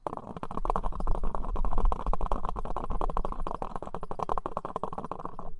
Descarga de Sonidos mp3 Gratis: canica 2.
canica-2-.mp3